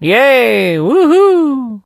sandy_start_vo_06.ogg